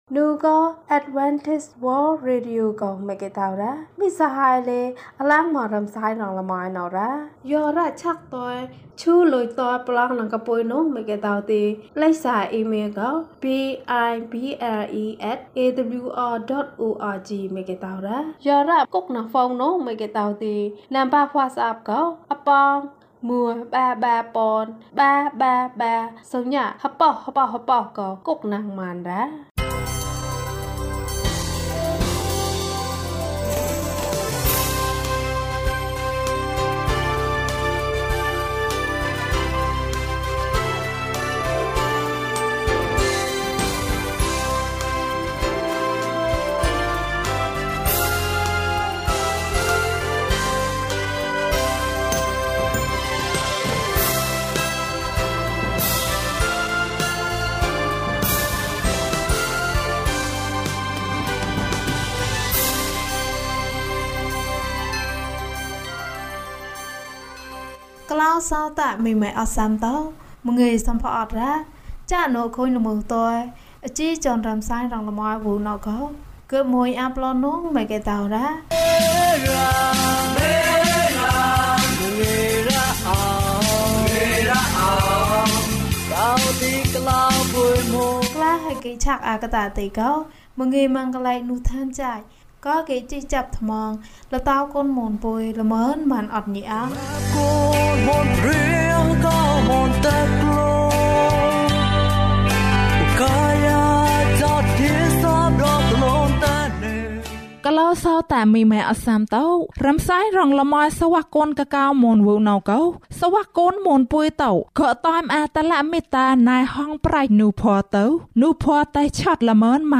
ဘုရားသခင်က ကောင်းတယ်။၀၂ ကျန်းမာခြင်းအကြောင်းအရာ။ ဓမ္မသီချင်း။ တရားဒေသနာ။